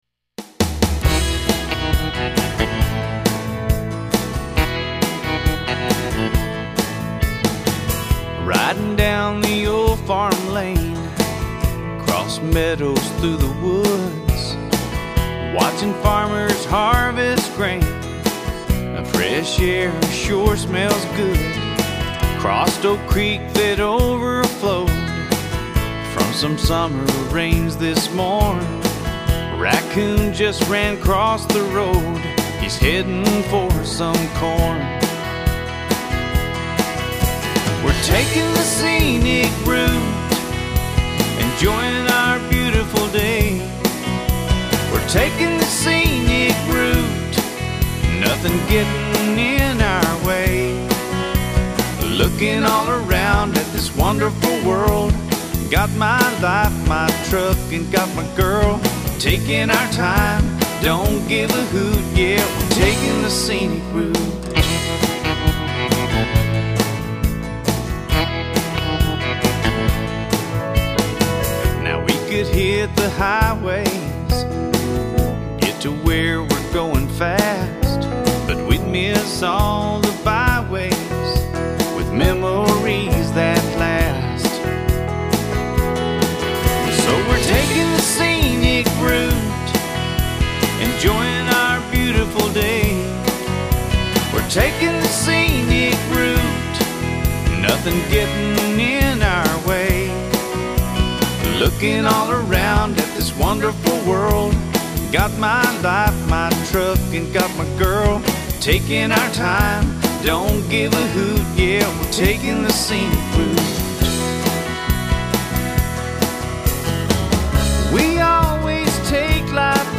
Complete Demo Song, with lyrics and music